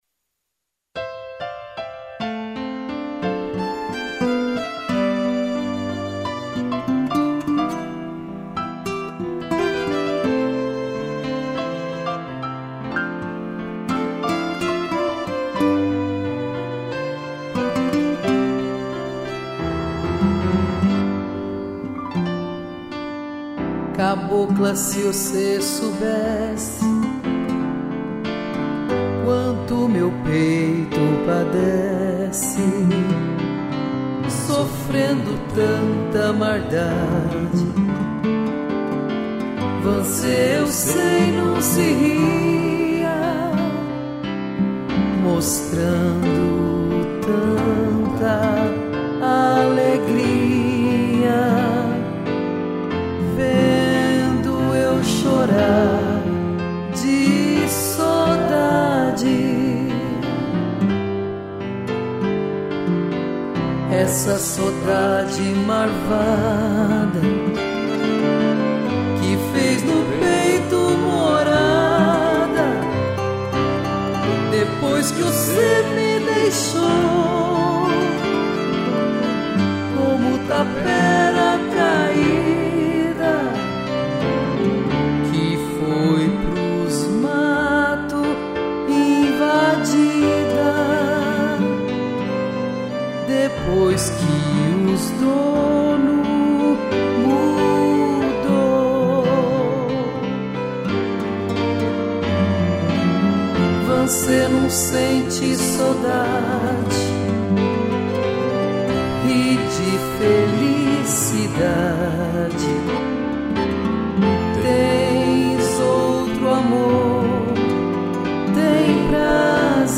voz e violão
piano, cello e violino